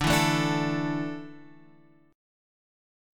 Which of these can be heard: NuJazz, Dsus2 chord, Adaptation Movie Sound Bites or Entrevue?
Dsus2 chord